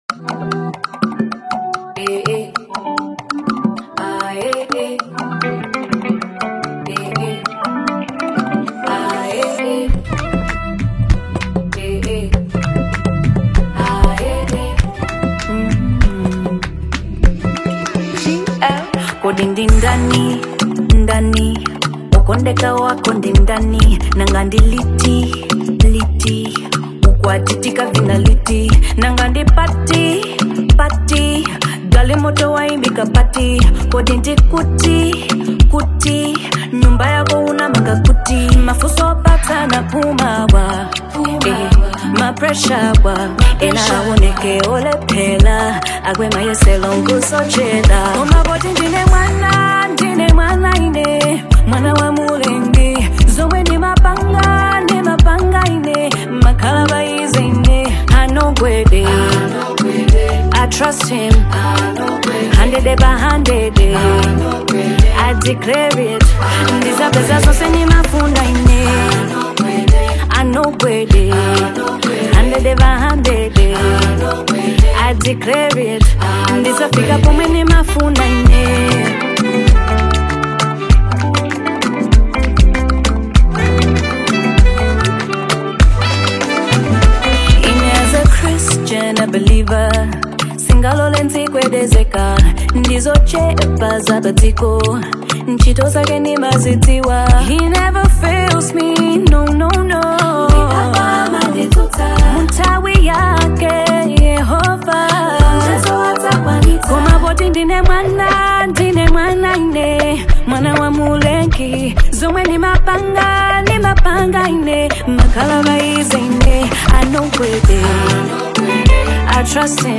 Genre: African Music